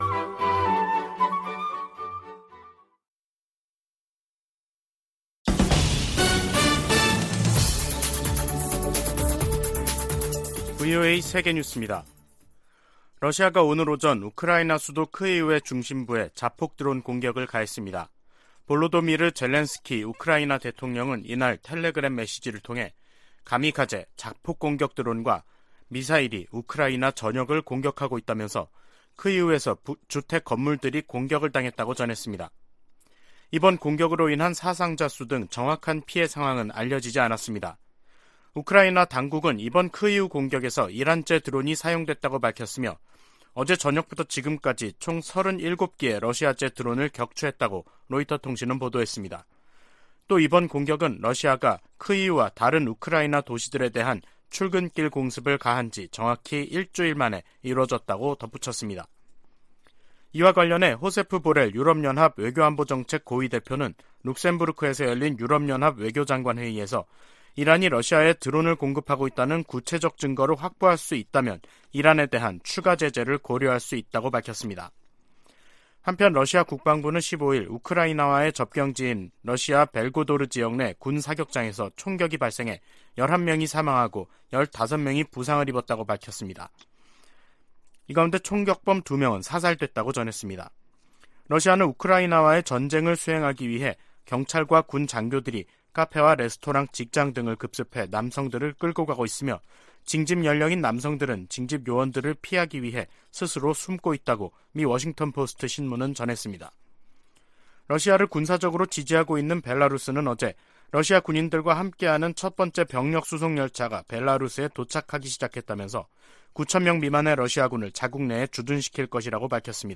VOA 한국어 간판 뉴스 프로그램 '뉴스 투데이', 2022년 10월 17일 2부 방송입니다. 북한이 연이어 9.19 남북 군사합의를 노골적으로 위반하는 포 사격에 나서면서 의도적으로 긴장을 고조시키고 있습니다. 미 국무부는 북한에 모든 도발을 중단할 것을 촉구하면서 비핵화를 위한 외교와 대화에 여전히 열려 있다는 입장을 재확인했습니다. 유엔은 북한의 안보리 결의 위반을 지적하며 대화 재개를 촉구했습니다.